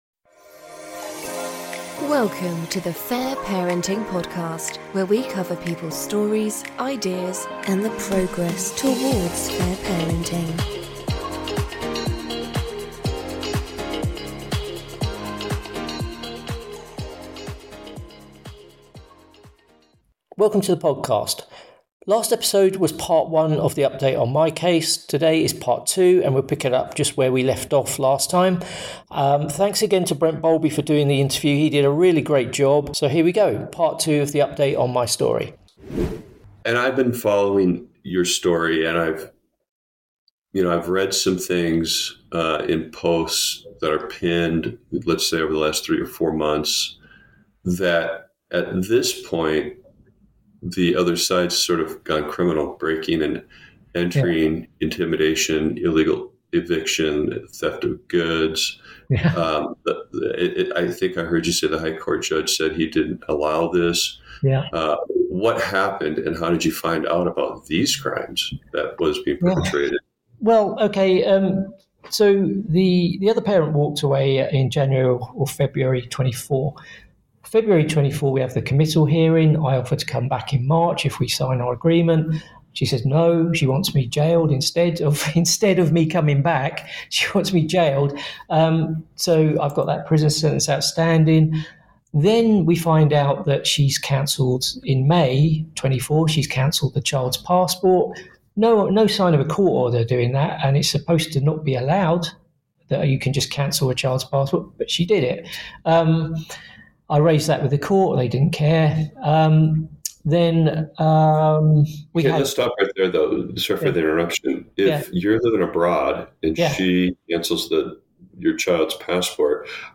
Story: An Interview Updating My Own Story (Part 2)